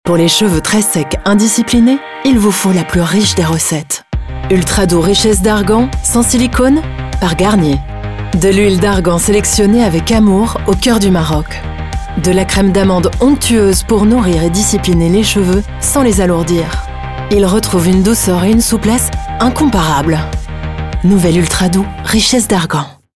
Voix off
7 - 53 ans - Contralto
Accent Belge